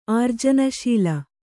♪ ārjanaśila